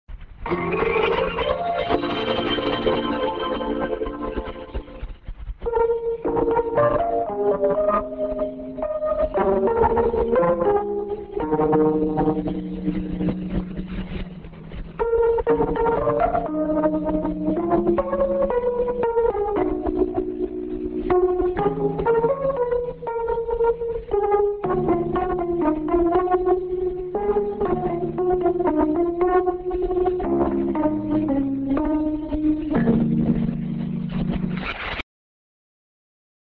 End IS(R.Norway Int.)->s/off =>b